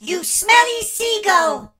darryl_hurt_vo_06.ogg